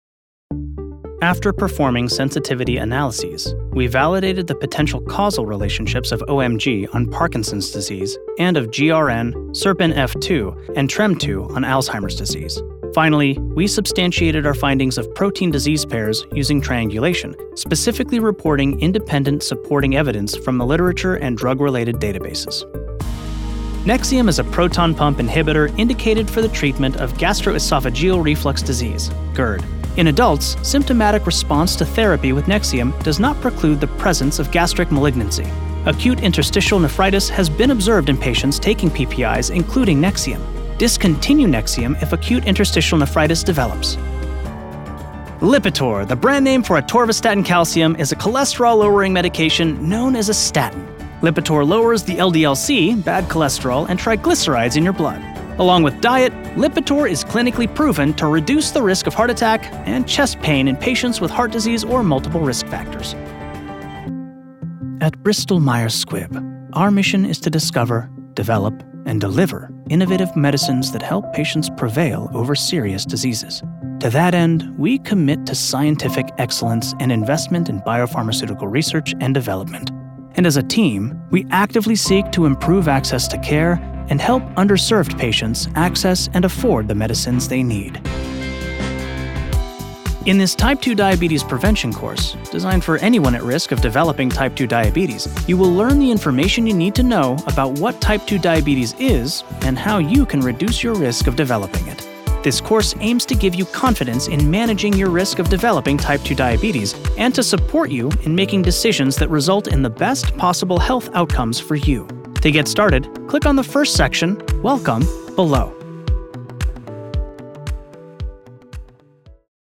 Male
I am a professional male voiceover with a broadcast-quality home studio.
Medical Narrations